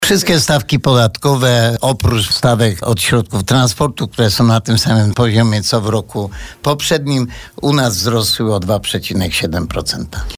Co ważne, w przyszłym roku w Żywcu wzrosną też inne podatki. – Wszystkie stawki podatkowe, oprócz podatku od środków transportowych, który pozostał na ubiegłorocznym poziomie, wzrosły u nas o 2,7% – mówi burmistrz.